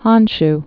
(hŏnsh)